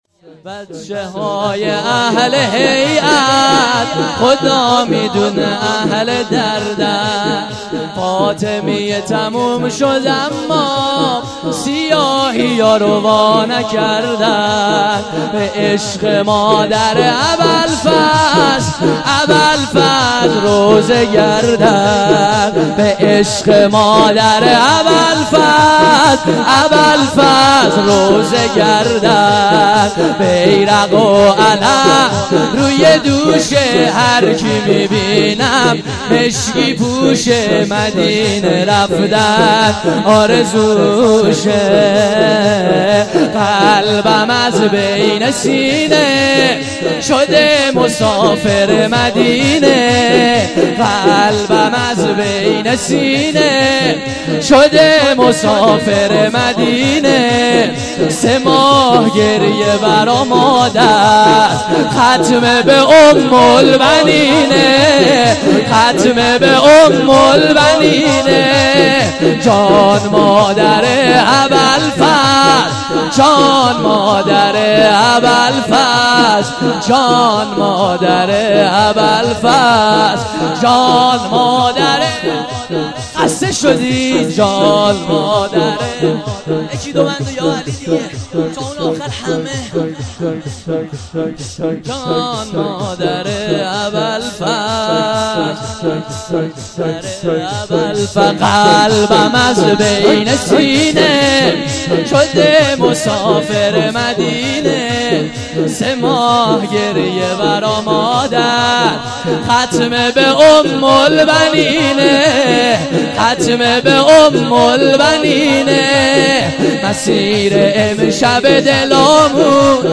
دو دمه